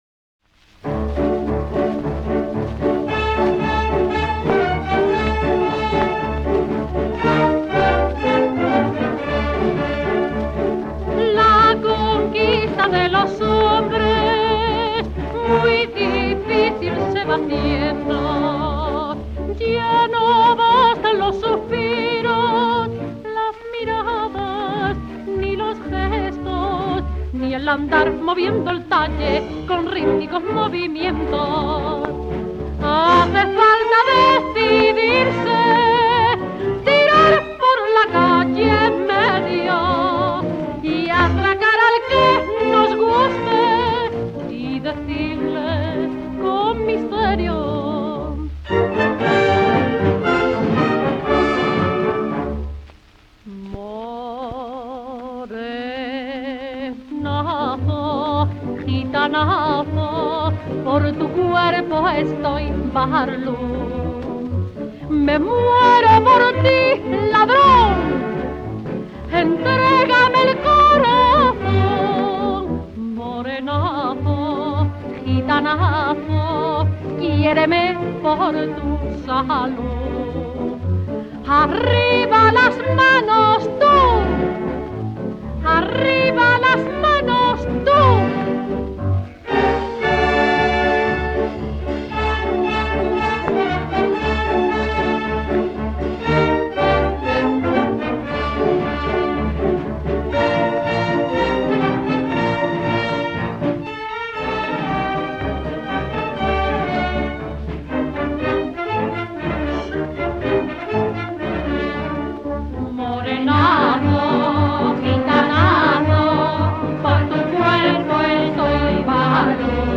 pasodoble
78 rpm